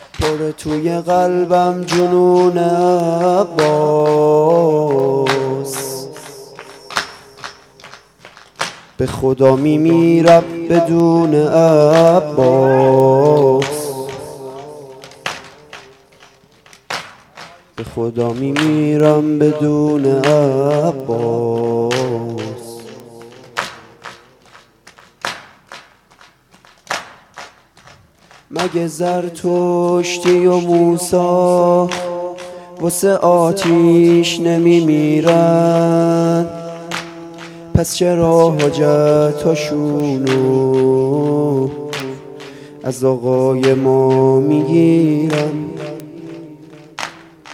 سه ضرب
شب تاسوعا ماه محرم